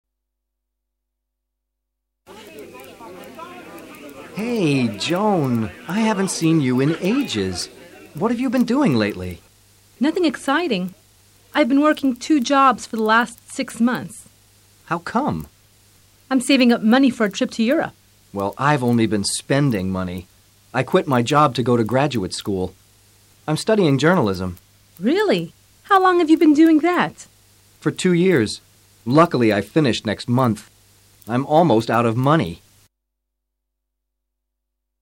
Freddy y Joan hace tiempo que no se veían y se encuentran en una fiesta. Escucha con atención y repite luego el diálogo.